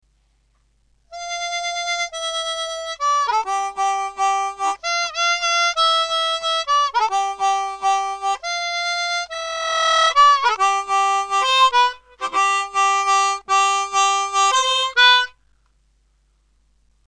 Full tab on a 3 octave Hohner CX12 Chromatic
6D   6B 5D..4D   3B   3B   3B..3B   (with hand vibrato)